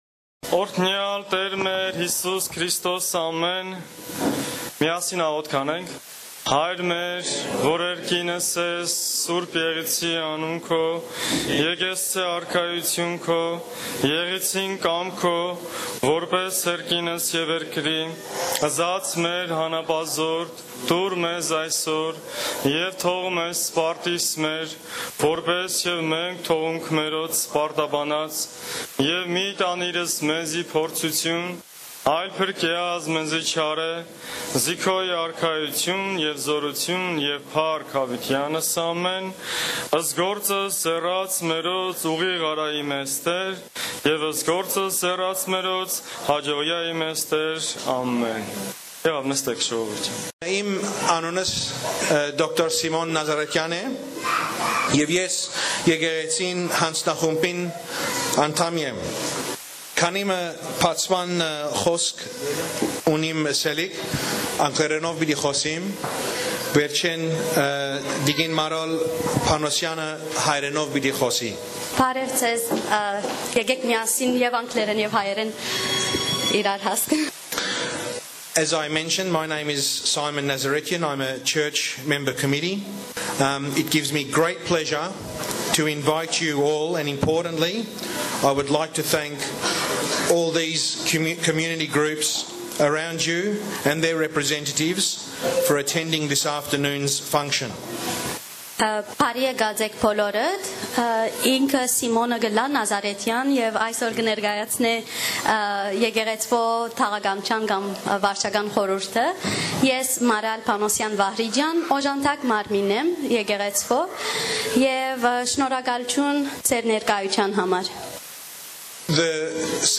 On Sunday March 26, a welcoming event was held in the hall of St Marys Armenian Apostolic Church in Melbourne. Community organisations in Melbourne presented themselves and their activities to Armenian refugees from Syria and Iraq, who in recent months, have settled in and around Melbourne.